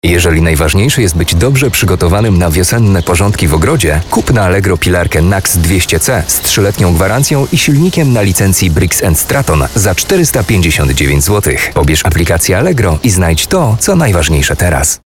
Mężczyzna 30-50 lat
Głos lektora charakteryzuje się głęboką, ciepłą alikwotyczną barwą. Potrafi on modulować ton i tempo mowy, dostosowując się do kontekstu - od dynamicznych reklam i zwiastunów po spokojniejsze, bardziej refleksyjne treści. Dzięki doświadczeniu scenicznemu interpretacja tekstu jest naturalna i przekonująca a dykcja klarowna.
Dynamiczny spot reklamowy